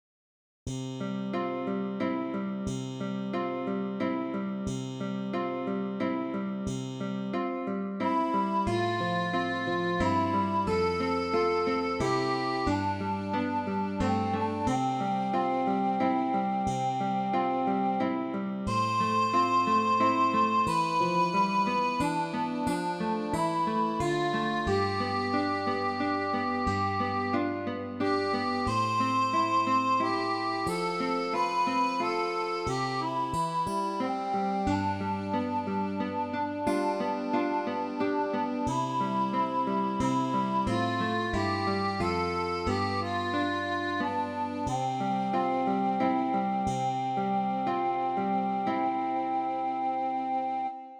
Midi File